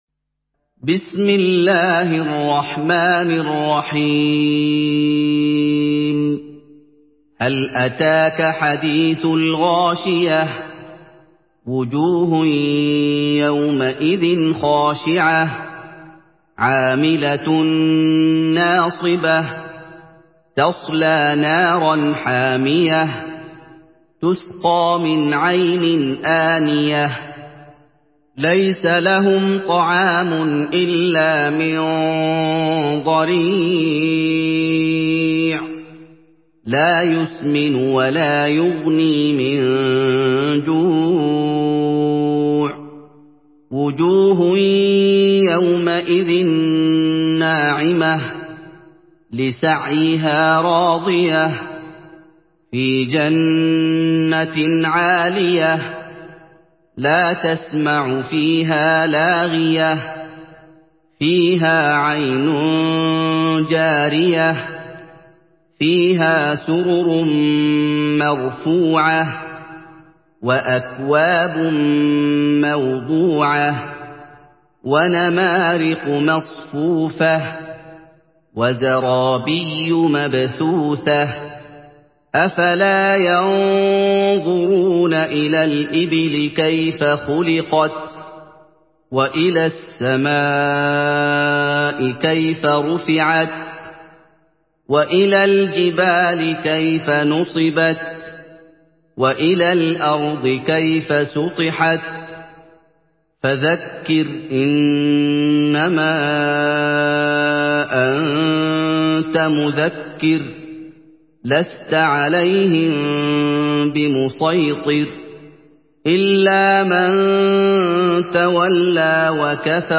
Surah Gaşiye MP3 by Muhammad Ayoub in Hafs An Asim narration.
Murattal